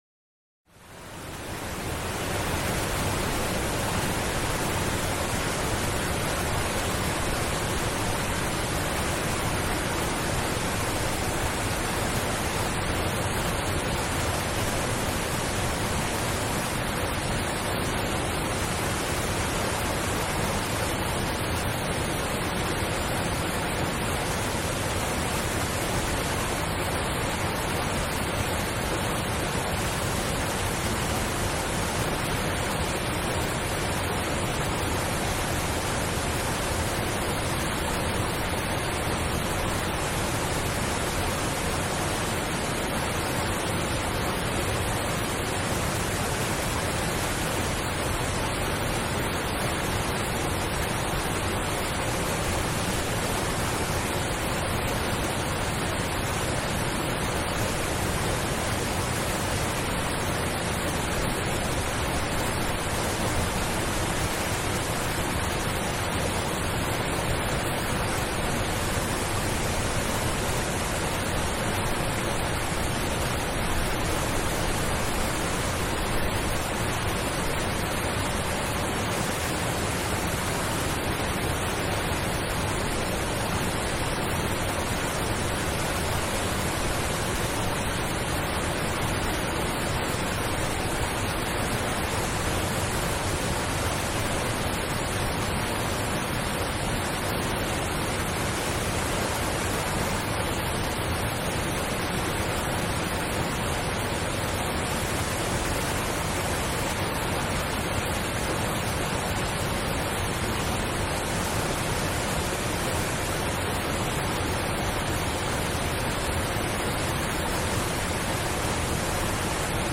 PARADOXE ENTSPANNUNG: Wildes Wasser-Kraft mit kraftvollem Steinfall